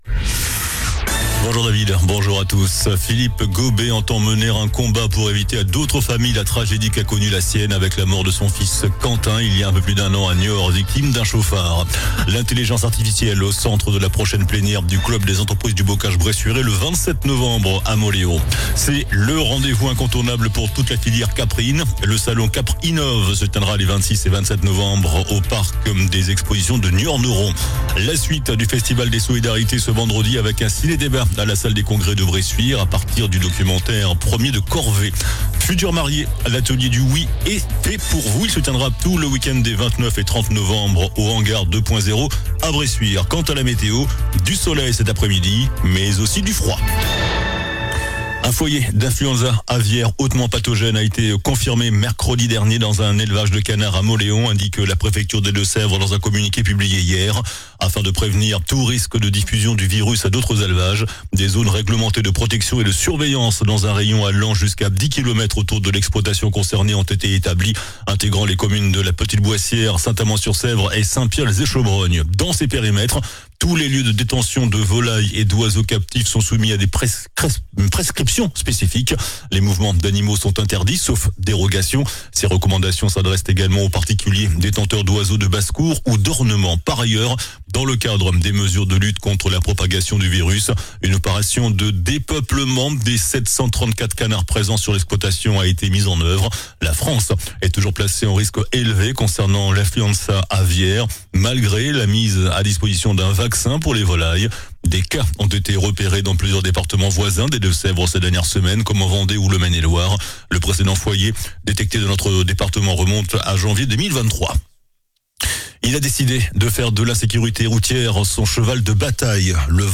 JOURNAL DU VENDREDI 21 NOV ( MIDI )